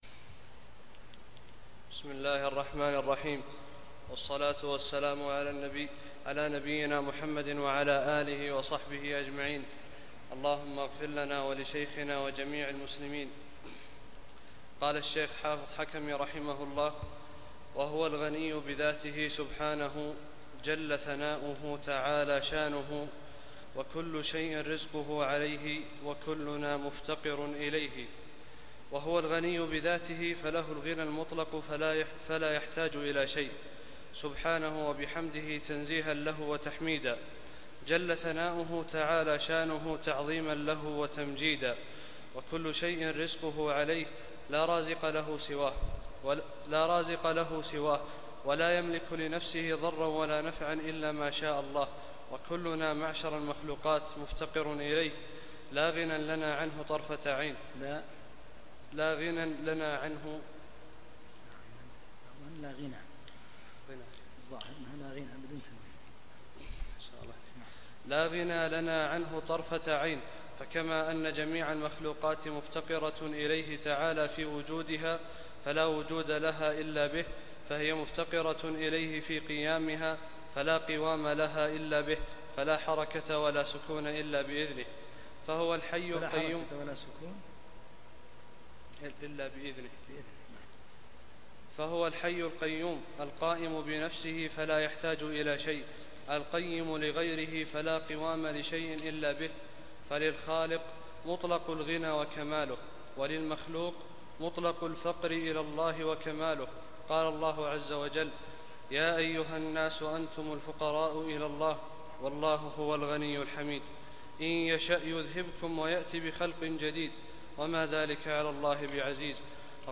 33- الدرس الثالث والثلاثون